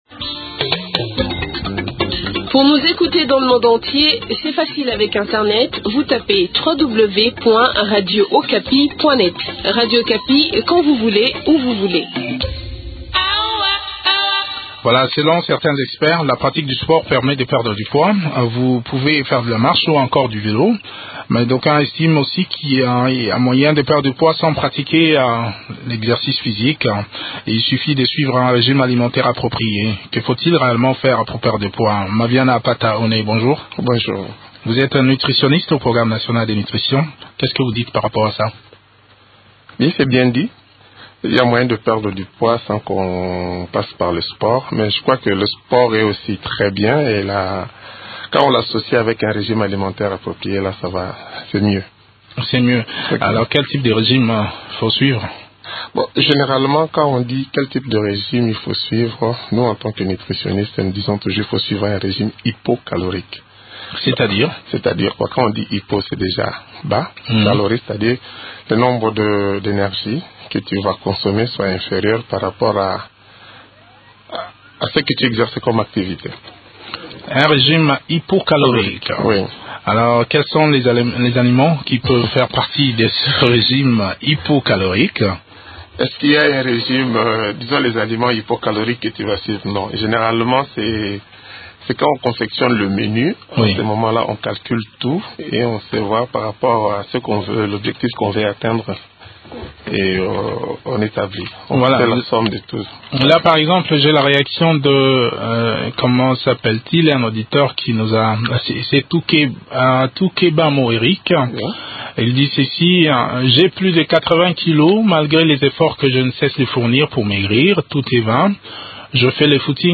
Il suffit de suivre un régime alimentaire approprié pour y parvenir. Que faut-il réellement faire pour perdre du poids sans recourir au sport ? Eléments de réponse dans cet entretien